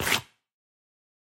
Звуки поскальзывания
Шорох скользящей обуви